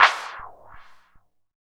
12 CLAP   -L.wav